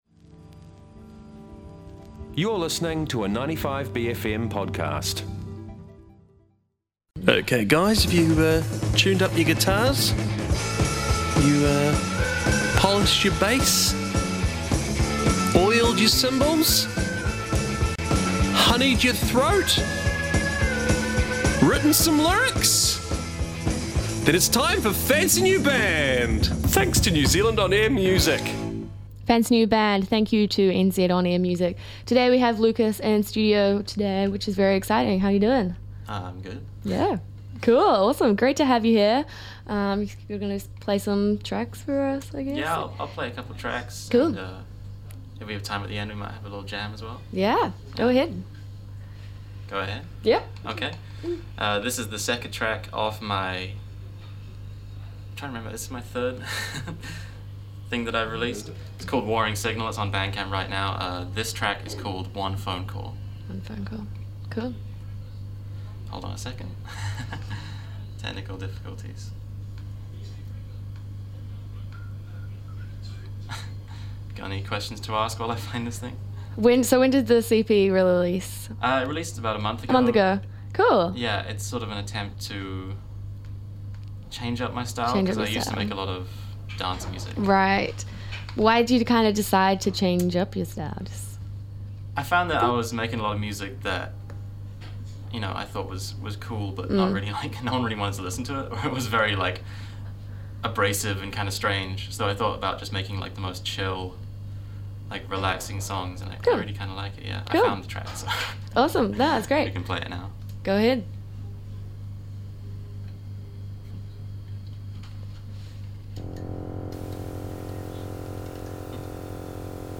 pops into the studio to play some chill tunes